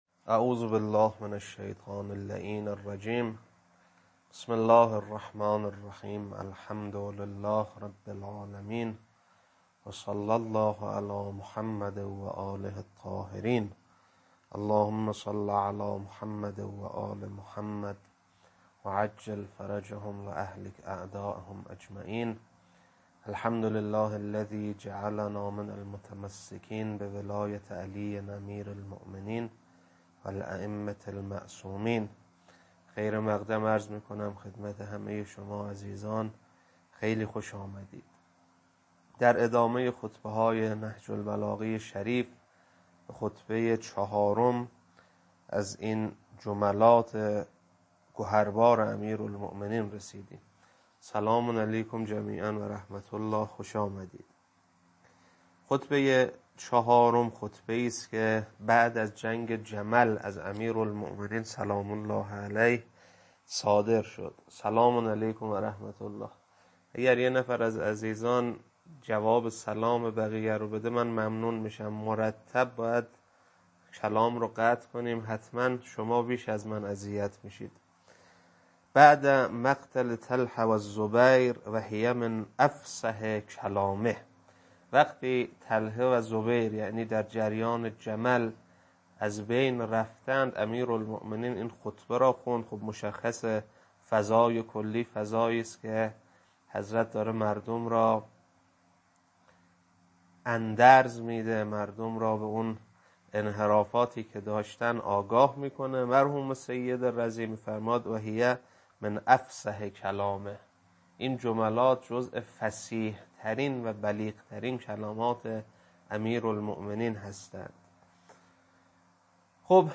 خطبه 4.mp3